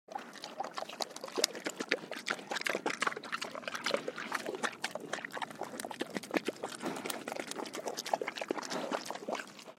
دانلود صدای مزرعه 30 از ساعد نیوز با لینک مستقیم و کیفیت بالا
جلوه های صوتی
برچسب: دانلود آهنگ های افکت صوتی طبیعت و محیط دانلود آلبوم صدای مزرعه روستایی از افکت صوتی طبیعت و محیط